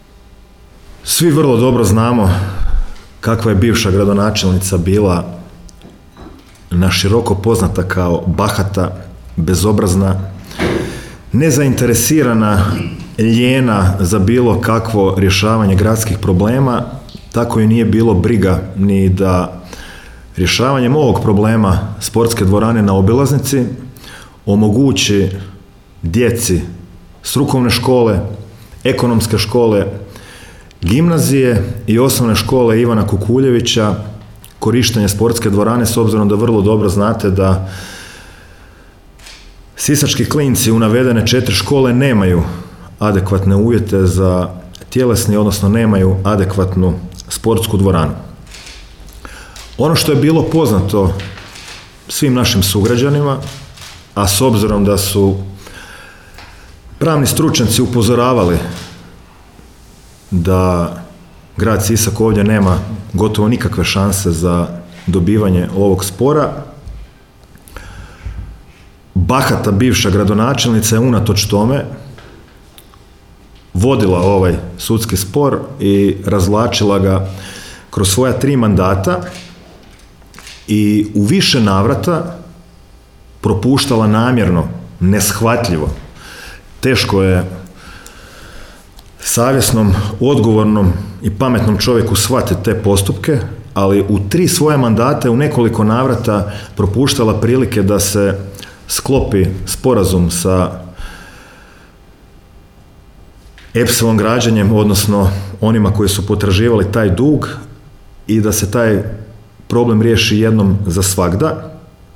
O presudi je, na tiskovnoj konferenciji održanoj u sisačkoj gradskoj upravi, govorio gradonačelnik Domagoj Orlić.